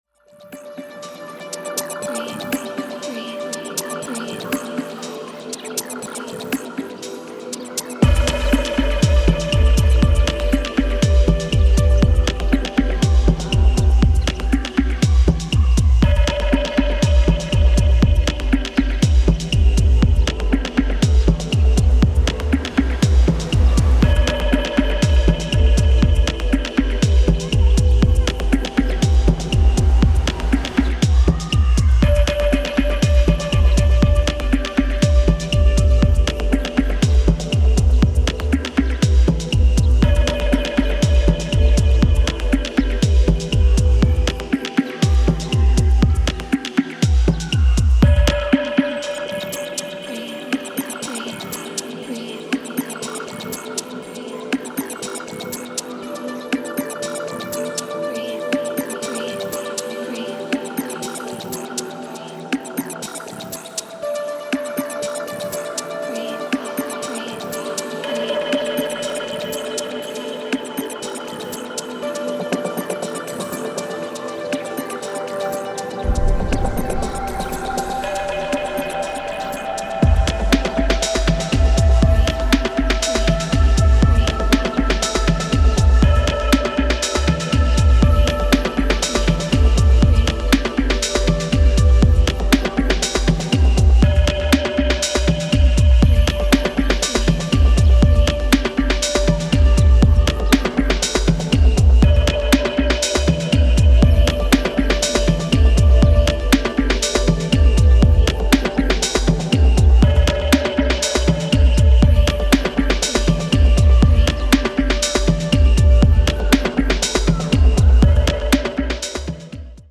Breakbeat , Drum n Bass , House